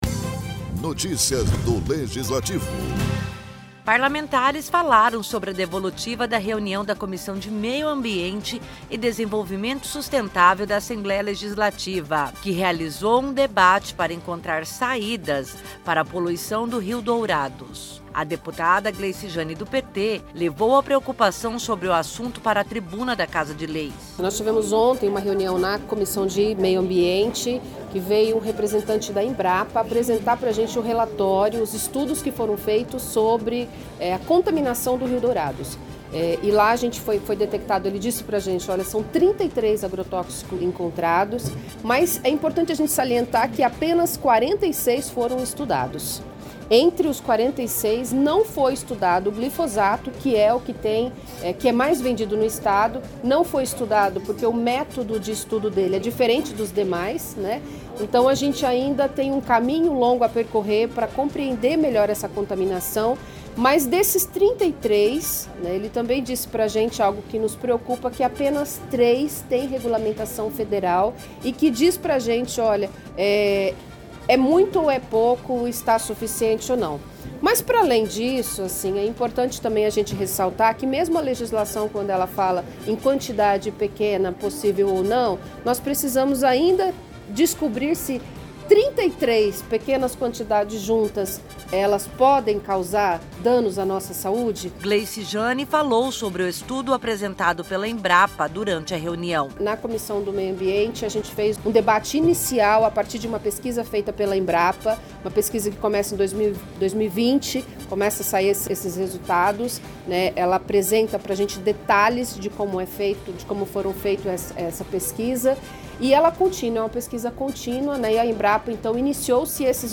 Poluição do rio Dourados é debatido na tribuna ALEMS